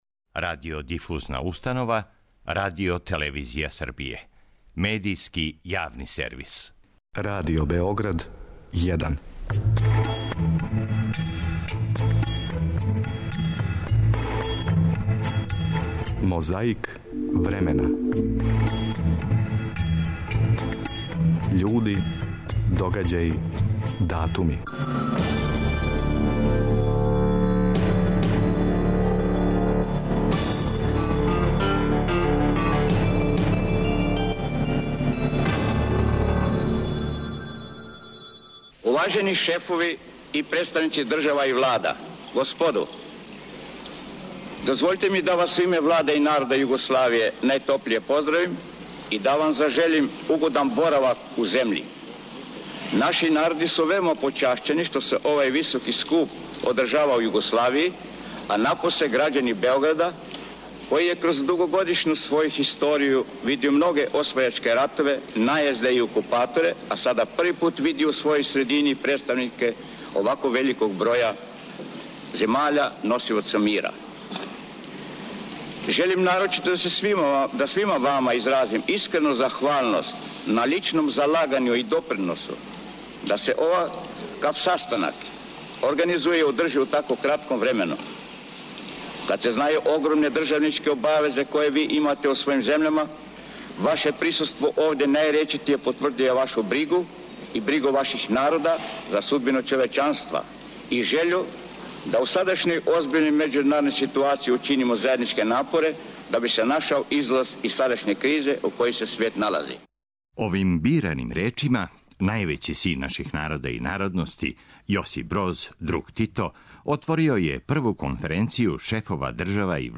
Мирослав Крлежа одржао је говор 2. септембра 1966. године на Цетињу. Слушамо снимак који су забележили аутори емисије "Књижевни фељтон".
Говорио је Добрица Ћосић, председник Савезне републике Југославије. 30. августа 1995.
Тим поводом, изјаву је дао генерал Ратко Младић. 2. септембар 2004.